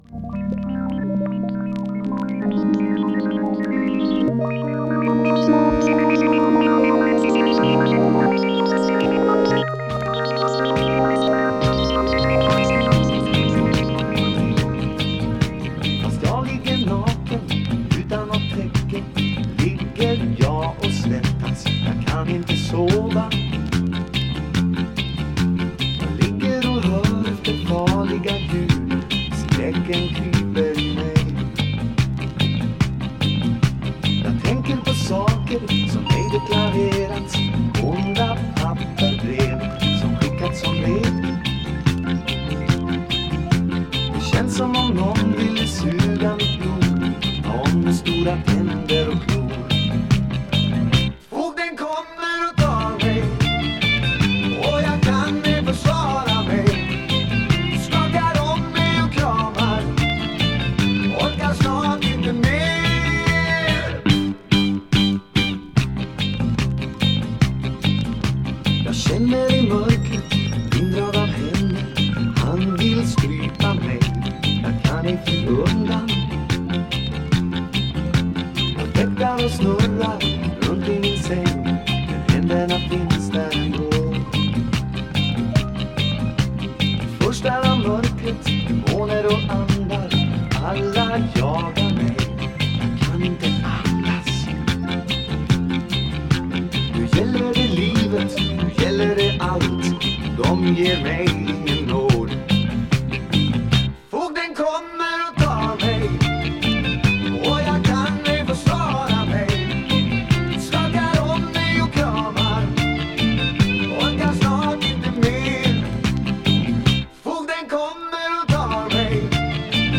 Vocals, Piano, Fender Rhodes 88,
Bass
Vocals, Drums, Percussion
Vocals, Guitars, Mandolin
Roland synt on A5